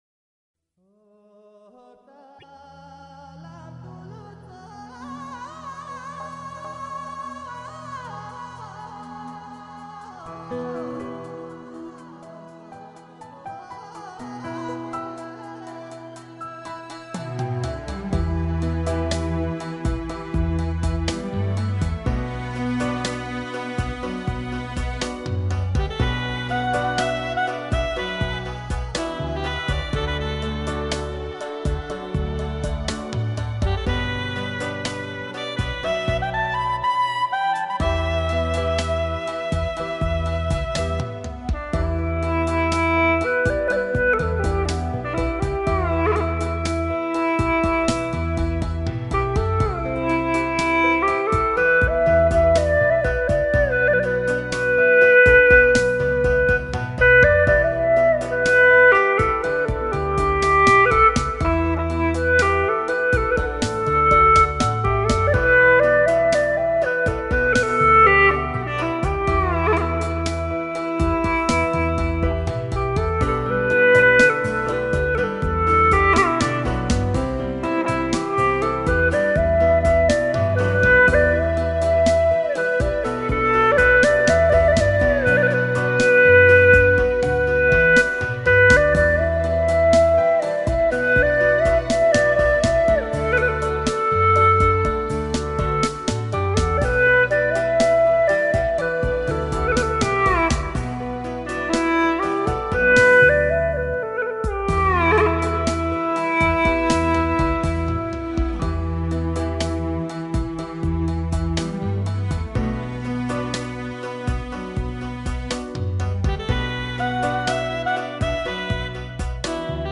【G调】